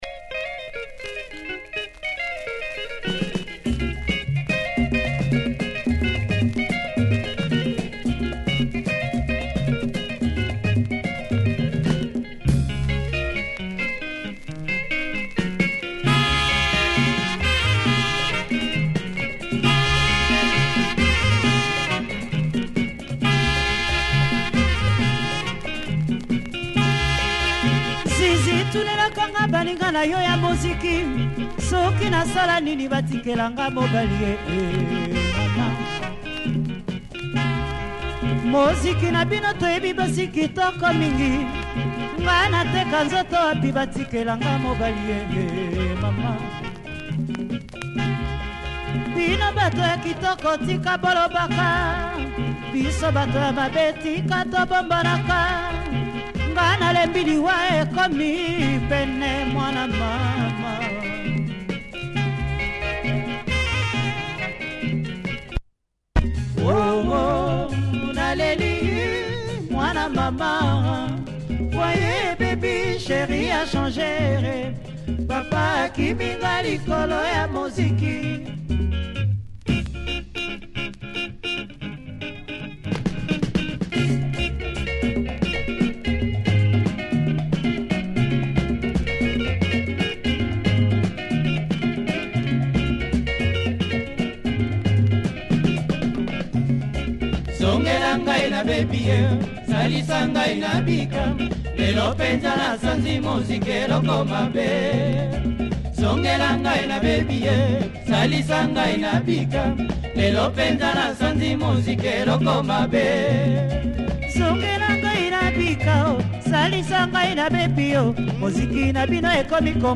lingala track